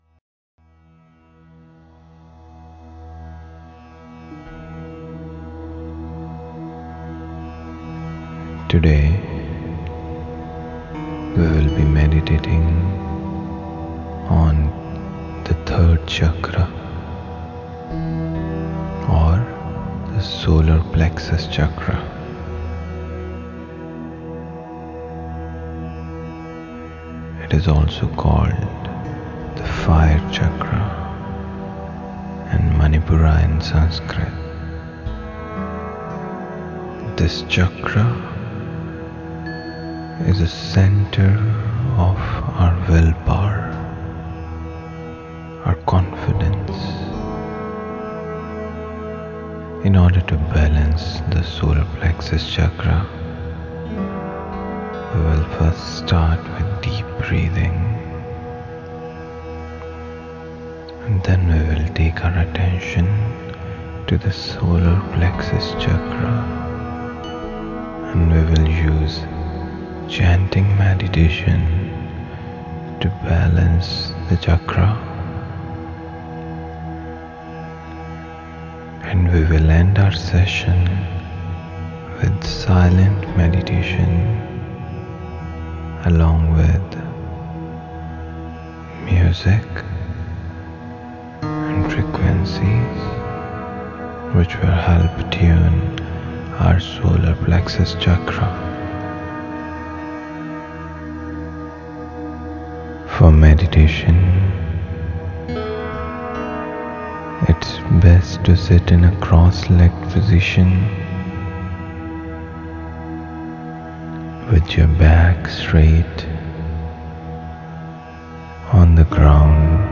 3SolarPlexusChakraHealingGuidedMeditationEN.mp3